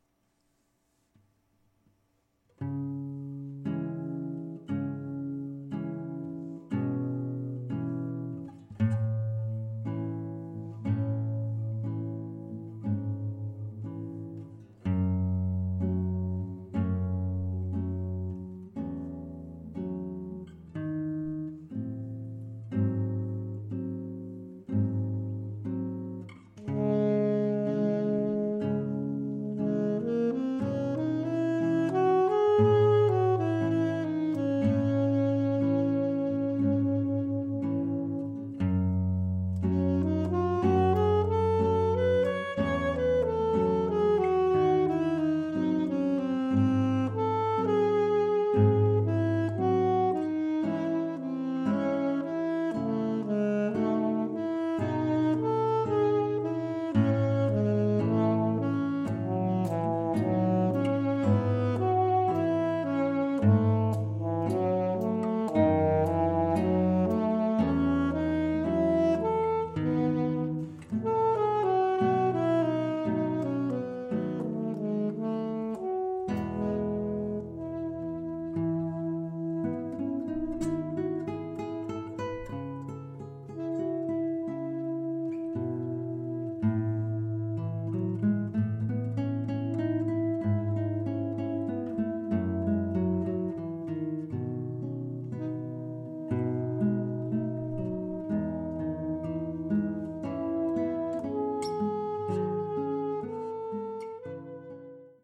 This project intends to open a place in the guitar repertoire for J.S. Bach’s sonatas for violin and harpsichord. Since the nature of this composition is that of a duet in a trio sonata character, I have chosen to pair the guitar with the saxophone.